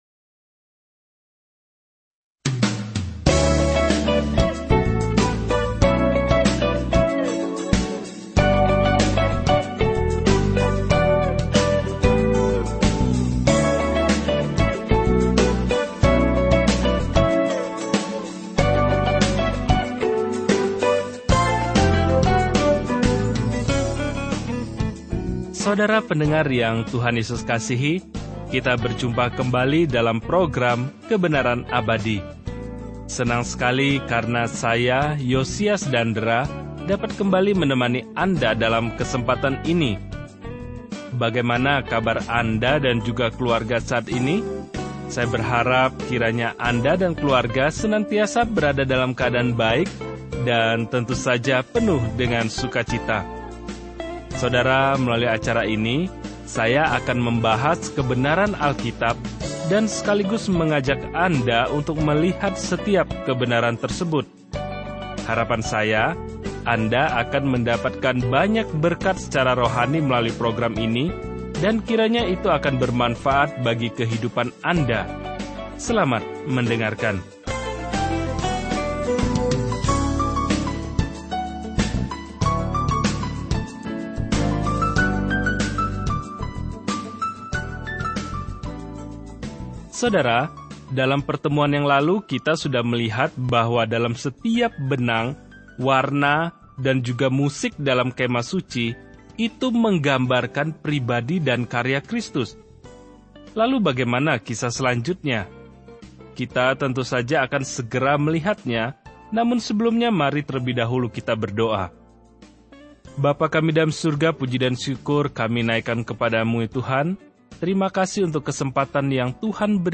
Program ini (“Kebenaran Abadi”) adalah bagian dari pengajaran Alkitab di seluruh dunia dari program TTB.
Program ini dirancang sebagai program radio setiap hari yang berdurasi 30 menit yang secara sistematis membawa pendengar kepada seluruh isi Alkitab.